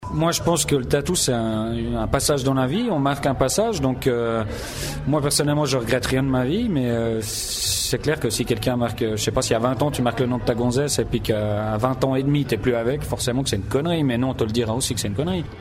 document 1 : registre (format MP3)